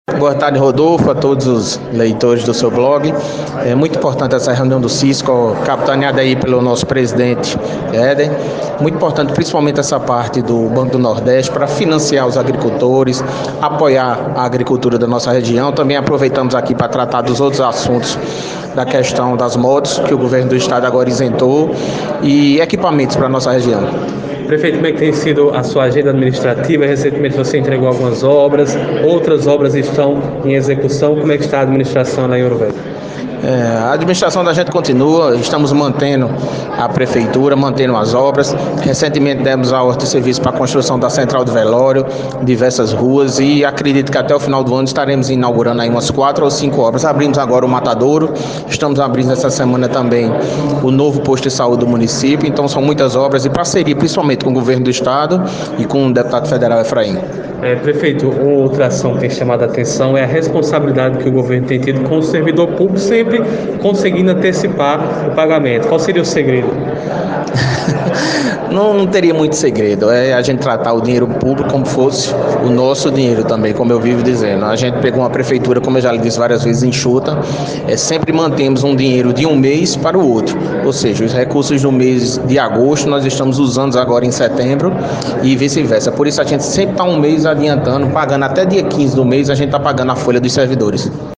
ENTREVISTA: Prefeito de Ouro Velho participa de reunião do CISCO